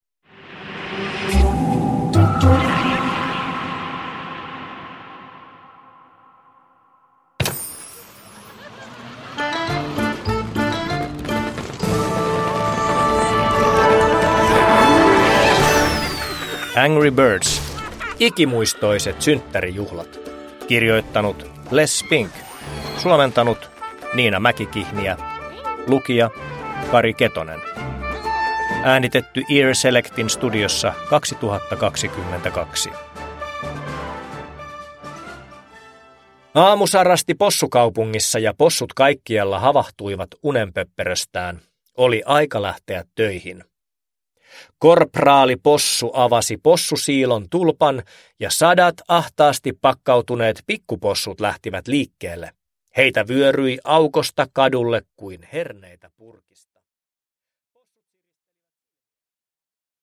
Angry Birds: Ikimuistoiset synttärijuhlat – Ljudbok – Laddas ner